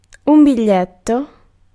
Vaihtoehtoiset kirjoitusmuodot not noit noyt Synonyymit banknote add (slangi) buck listen line use bill utility gloss enroll betoken make a note Ääntäminen US : IPA : /noʊt/ UK : IPA : /nəʊt/ Lyhenteet ja supistumat (laki) n.